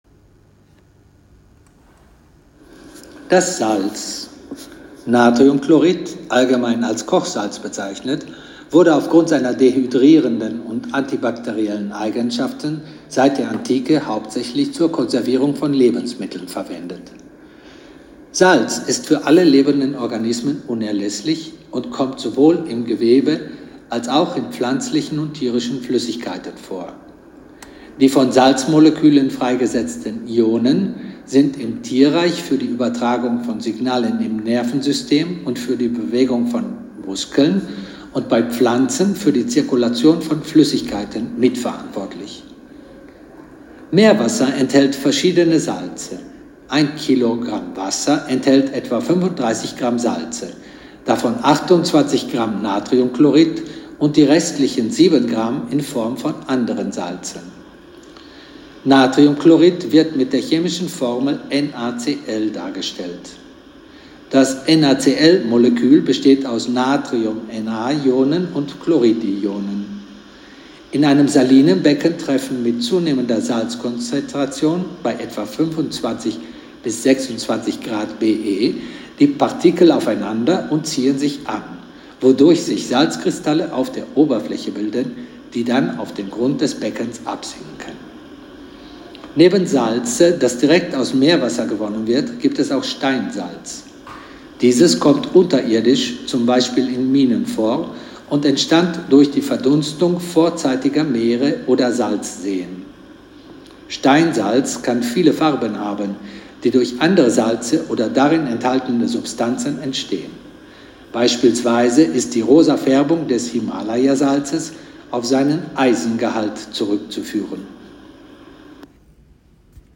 Audioguides auf Deutsch - Salina di Comacchio